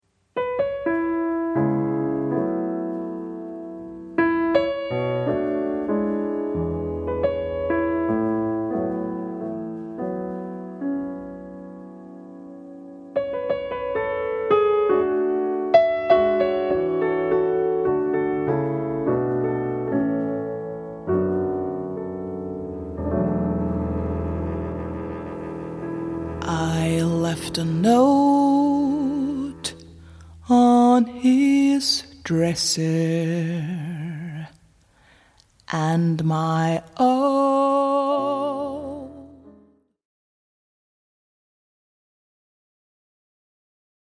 two songs flavoured with adventurous tempo changes
with a voice that changes from raw to sweet as honey
mit Elementen aus Blues, Pop, Folk und afrikanischer Musik
vocals
piano
bass
drums